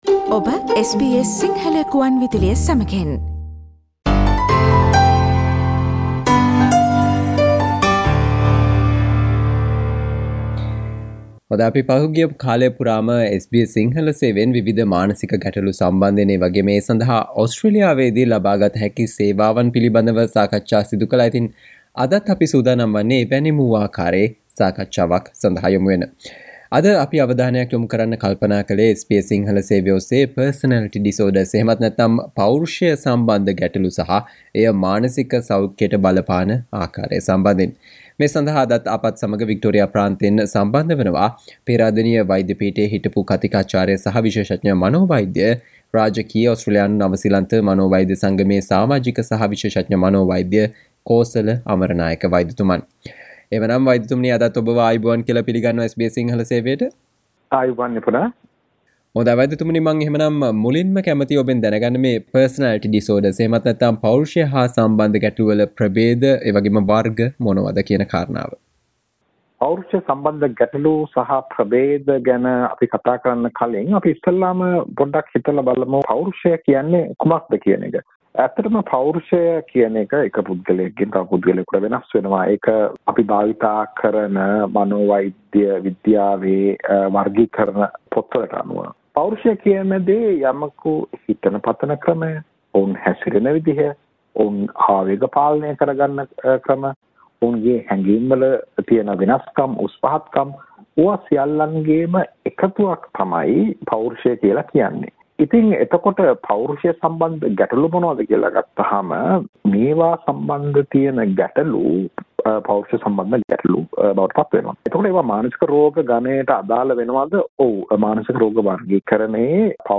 ඕස්ට්‍රේලියානුවන් 5%ක් පමණ පීඩා විඳින Personality disorders නොහොත් "පෞරුෂය සම්බන්ද ගැටලු" සහ එය මානසික සෞඛ්‍යයට බලපාන ආකාරය සම්බන්ධයෙන් ඔබ දැනුවත් වියයුතු කරුණු පිළිබඳව SBS සිංහල සේවය සිදුකල සාකච්චාව